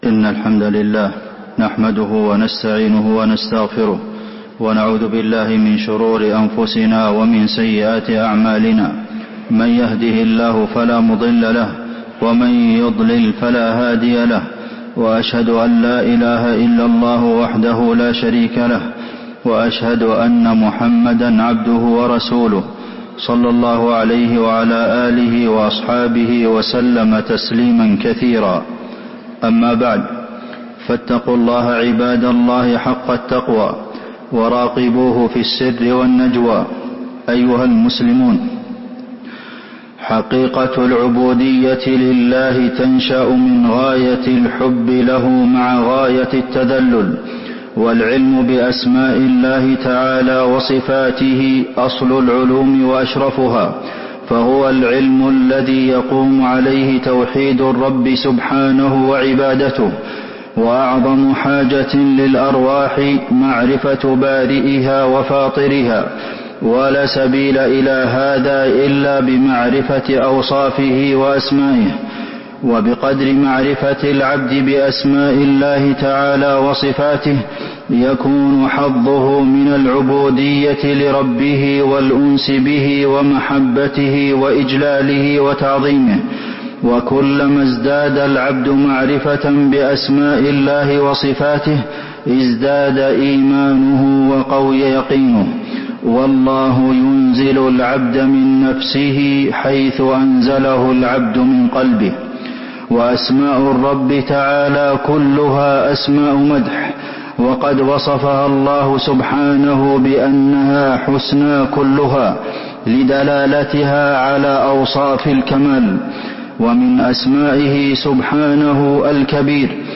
تاريخ النشر ١٢ ربيع الثاني ١٤٤٥ هـ المكان: المسجد النبوي الشيخ: فضيلة الشيخ د. عبدالمحسن بن محمد القاسم فضيلة الشيخ د. عبدالمحسن بن محمد القاسم من فضائل تكبير الله تعالى